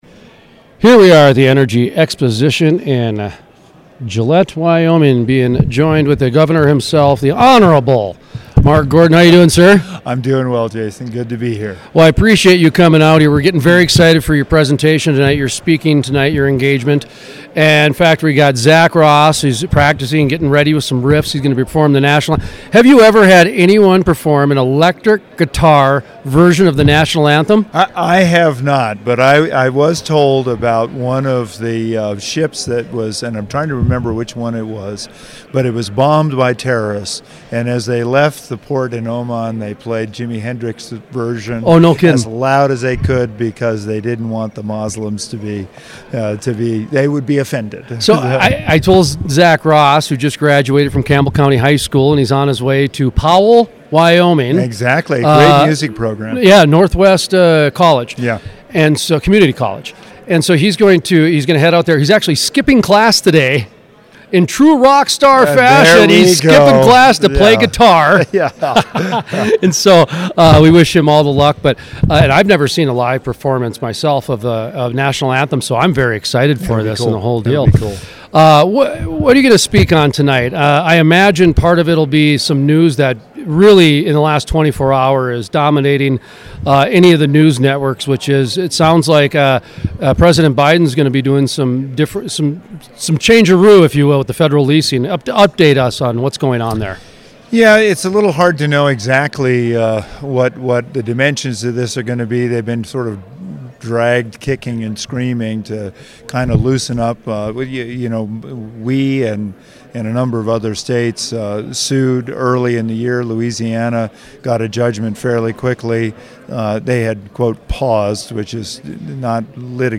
Full Length Interviews
Gov Gordon also explains how the state used the federal COVID money to empower and support small businesses in the oil and gas industry. The interview also talks about the impact of federal regulations on Wyoming.